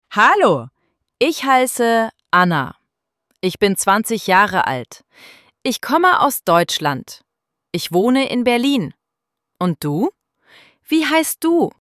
ElevenLabs_Text_to_Speech_audio-33.mp3